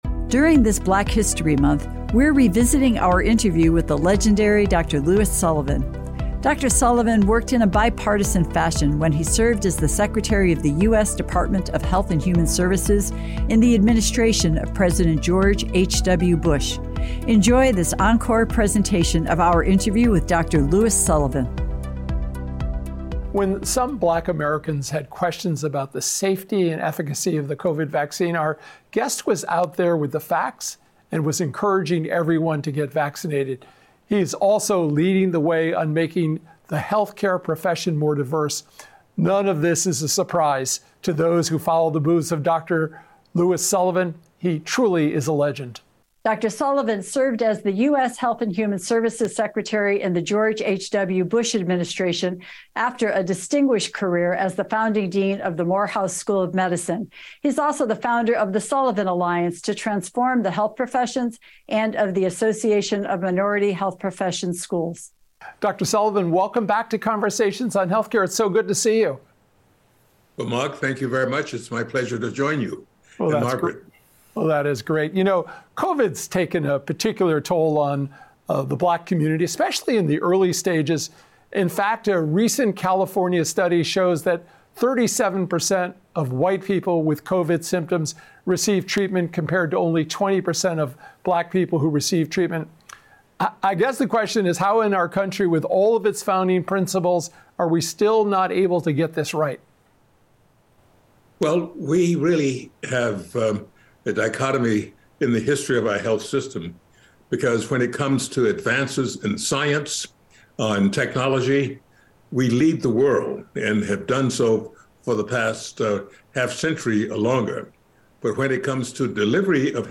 During Black History Month, we revisit our interview with Dr. Sullivan for lessons about leadership and the challenges that still exist in our healthcare system.